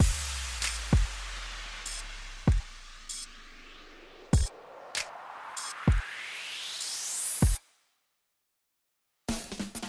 滑稽舞节拍介绍
描述：97bpm的慢速glitch hop，仍然是loadza groove。用Ableton制作的强劲的鼓声，在这个节奏和风格周围有一些循环，请欣赏
Tag: 97 bpm Dubstep Loops Drum Loops 1.66 MB wav Key : Unknown